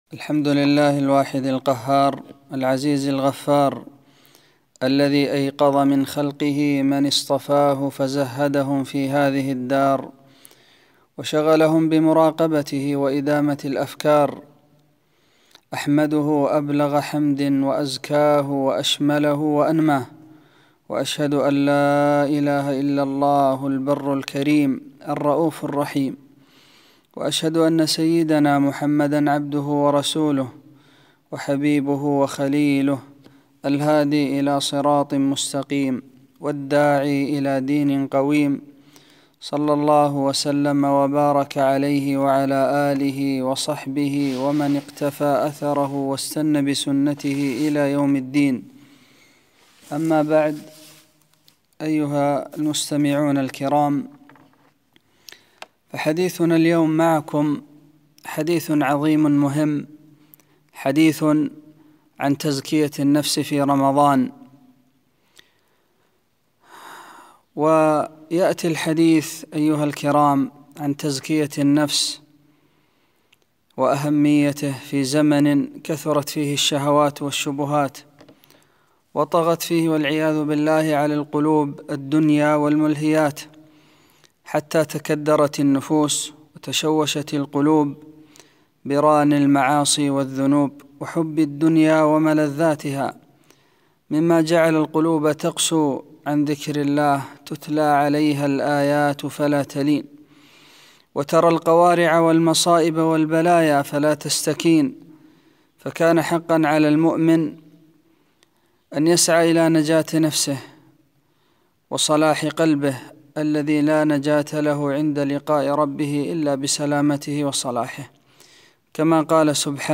محاضرة - رمضان وتزكية النفوس - دروس الكويت